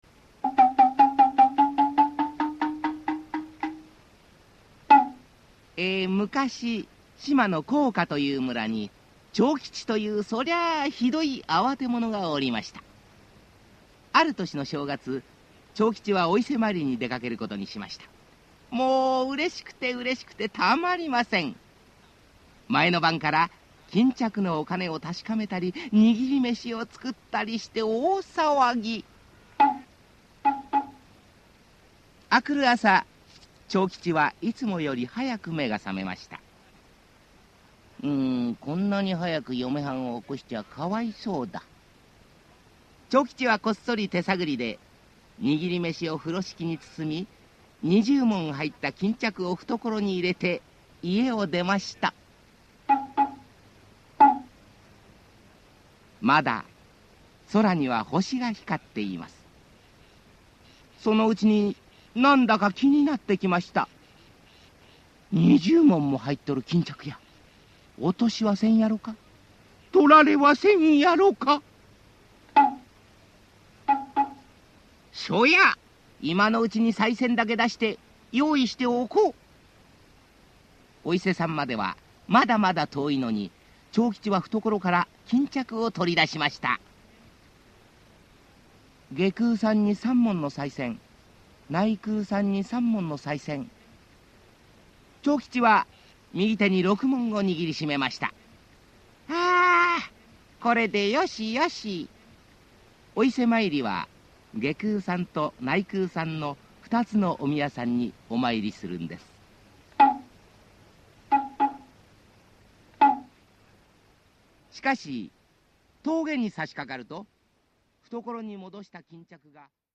[オーディオブック] あわての長吉